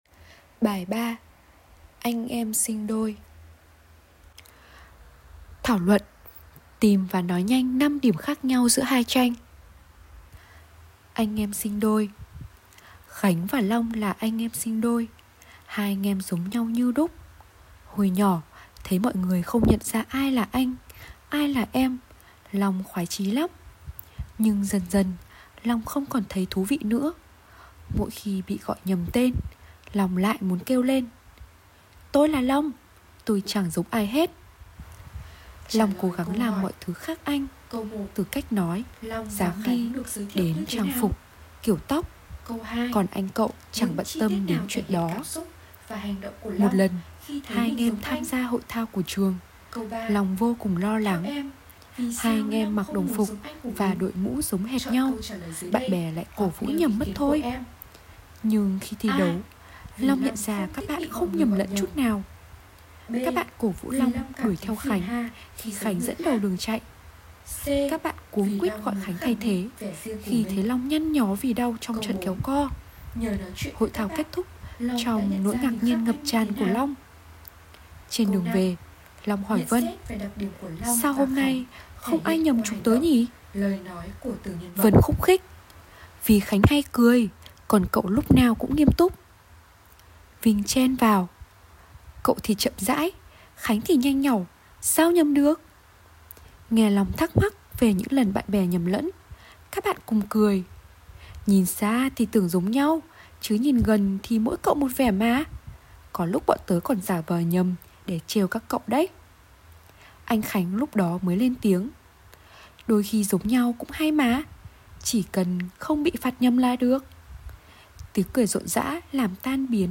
Sách nói | ANH EM SINH ĐÔI - TIẾNG VIỆT 4